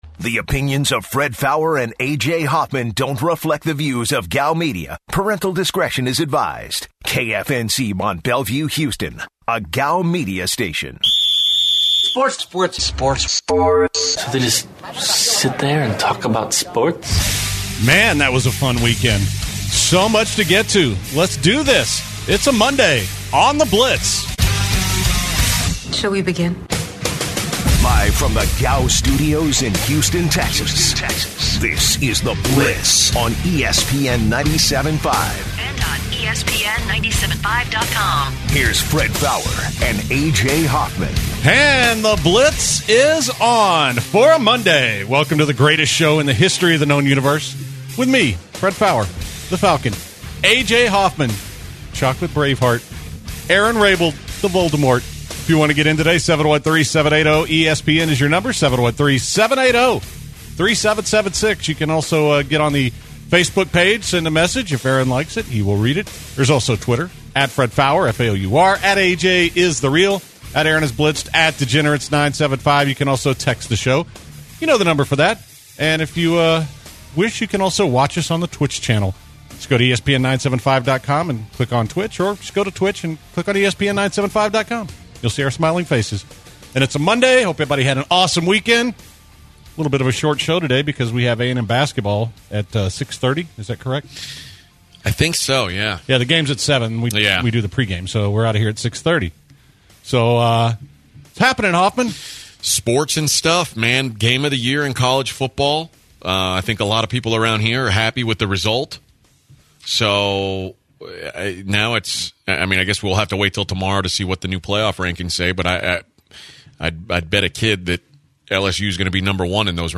The guys kick off the show reacting to LSU’s huge win over Alabama on Saturday and its ramifications on the College Football Playoff. Next, they react to Lamar Jackson’s latest absurd outing on Sunday and take some calls.